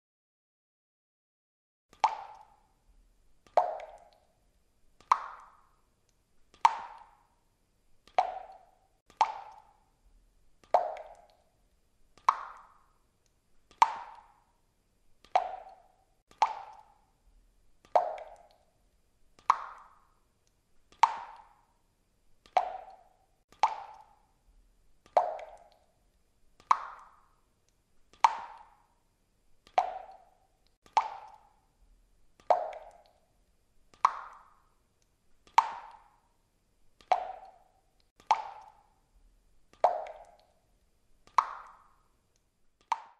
دانلود آهنگ چکه چکه قطره آب 1 از افکت صوتی طبیعت و محیط
جلوه های صوتی
دانلود صدای چکه چکه قطره آب 1 از ساعد نیوز با لینک مستقیم و کیفیت بالا